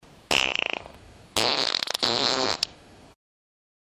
A fart that looks like a woodpecker.
a-fart-that-looks-like-36uwd5ho.wav